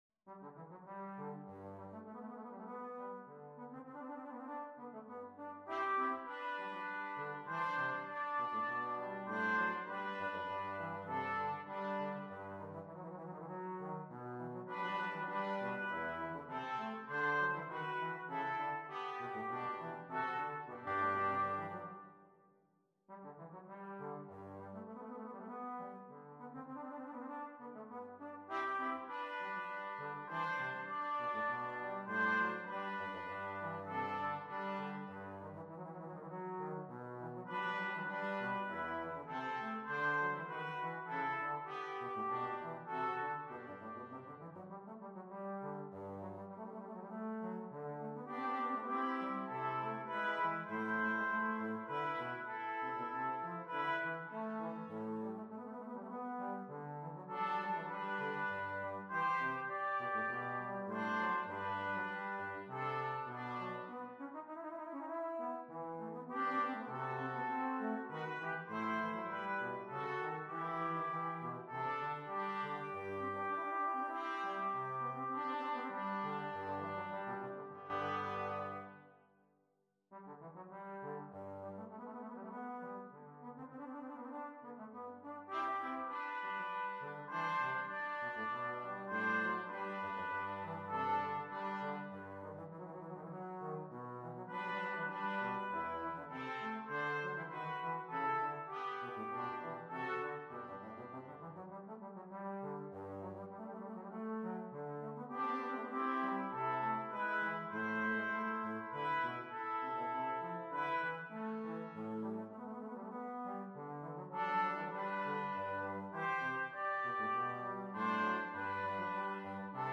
Free Sheet music for Brass Quartet
Trumpets in CTrombones
Brass Quartet  (View more Intermediate Brass Quartet Music)
Classical (View more Classical Brass Quartet Music)